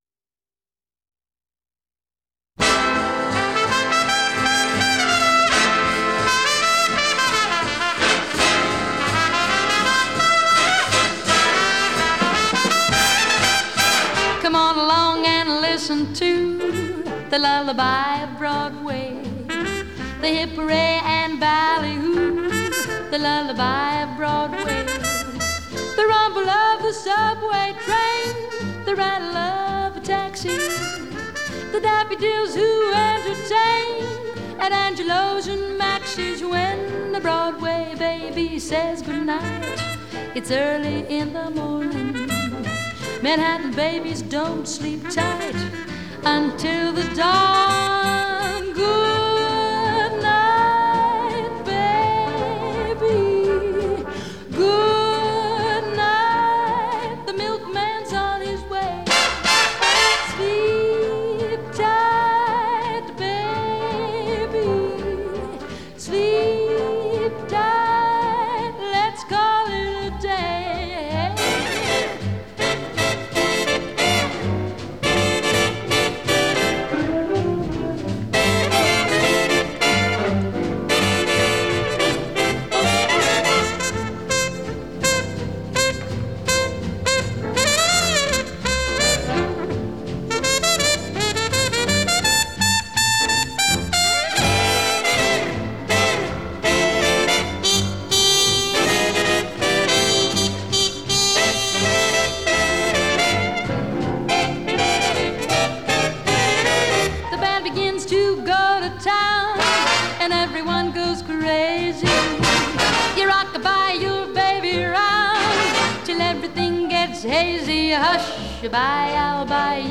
(record single)